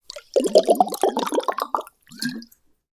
Звук наливания воды или напитка из бутылки в стакан или емкость